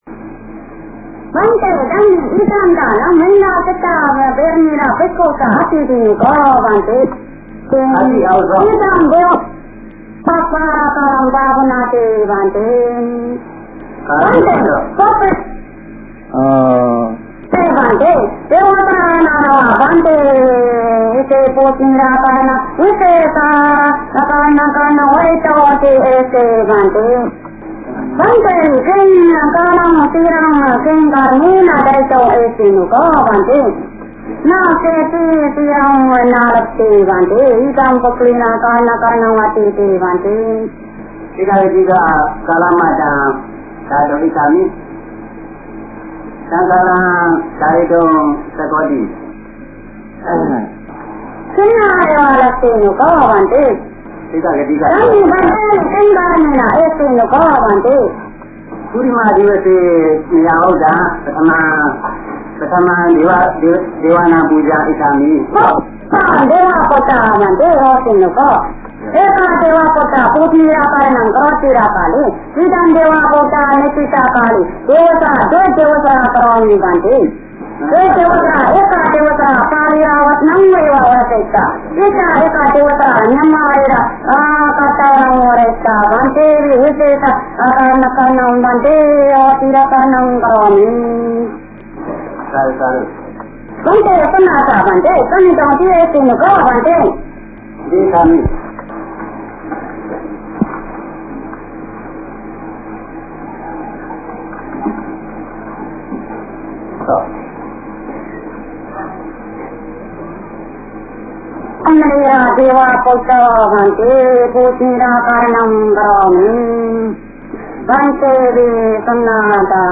The chanting style is similar to the one by sotāpanna deva recorded during the conversation with Mahasi Sayadaw.